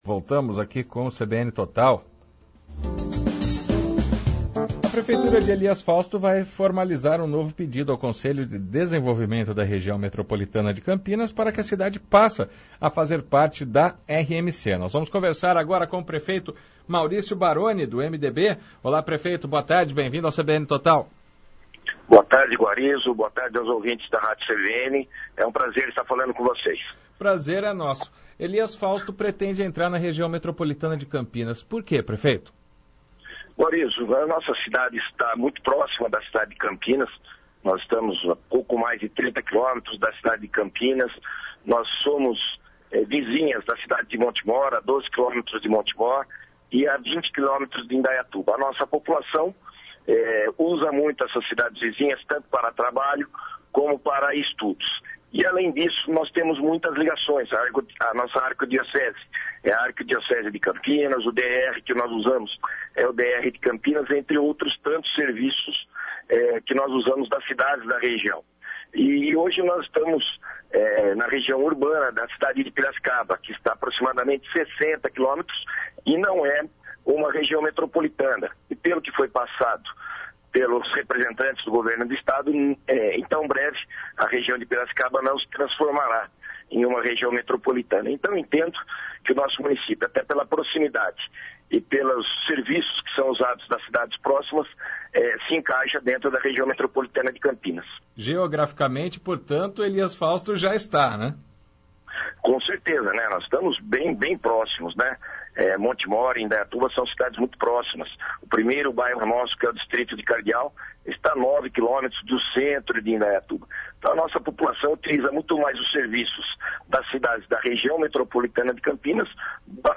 Prefeito de Elias Fausto, Mauricio Baroni (MDB) fala sobre o pedido de inclusão na RMC